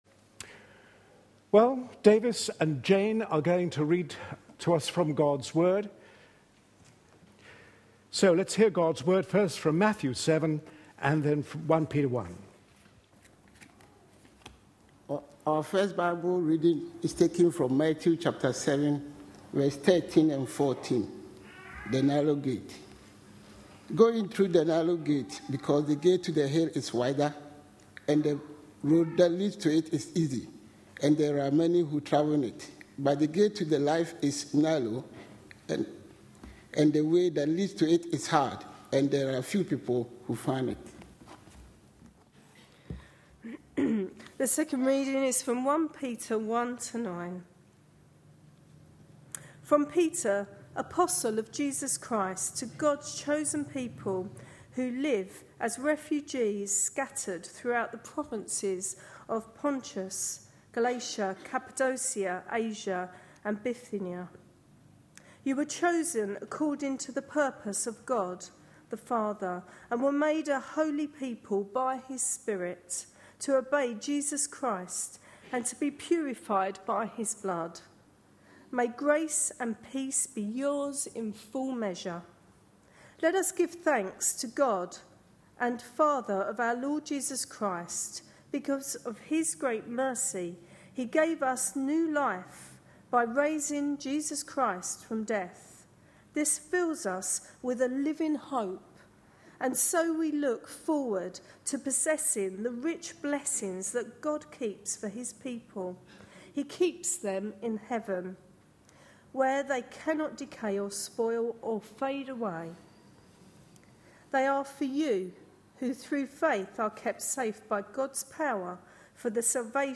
A sermon preached on 30th September, 2012, as part of our The Message of Peter for Today series.